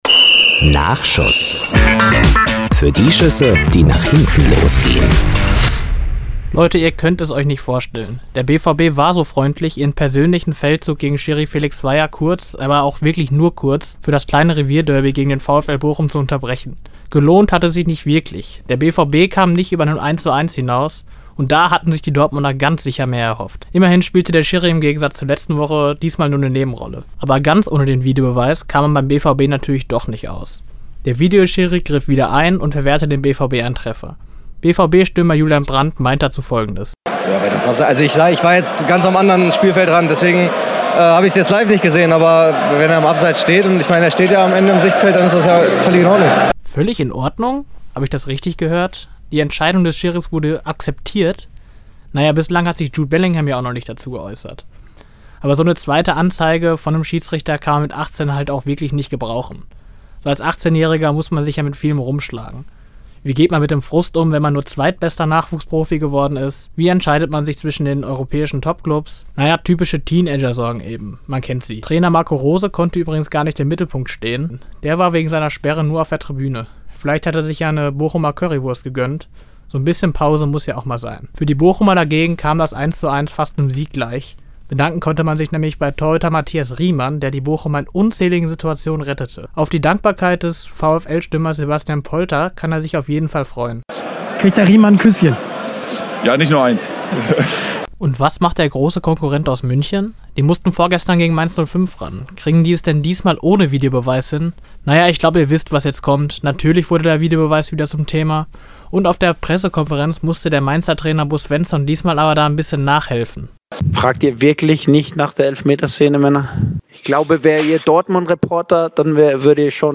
Glosse  Ressort